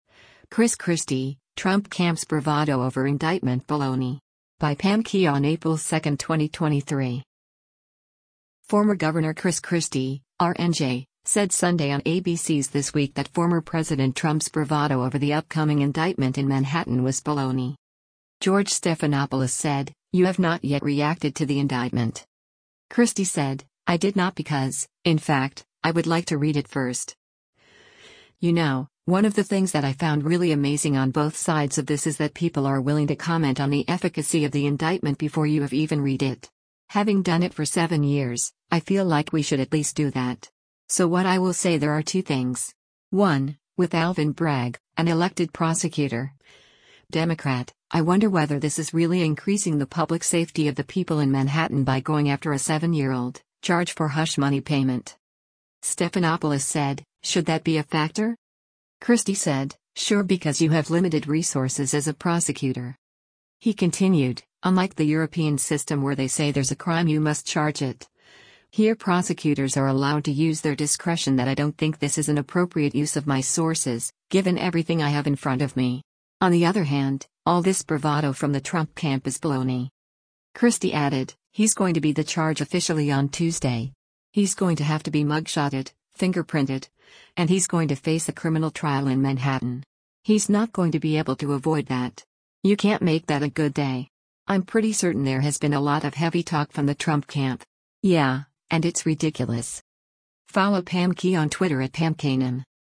Former Gov. Chris Christie (R-NJ) said Sunday on ABC’s “This Week” that former President Trump’s “bravado” over the upcoming indictment in Manhattan was “baloney.”